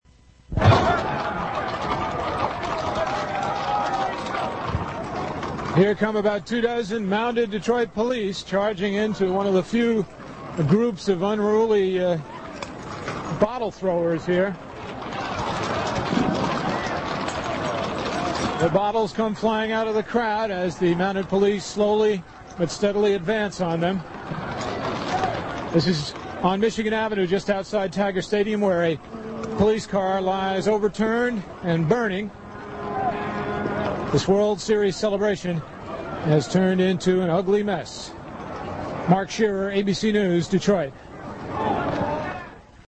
That’s how audio was fed back to the studio, where someone rolled a tape deck.
The friendly folks welcomed me in and watched as I unscrewed the mouthpiece on their living room telephone to file this: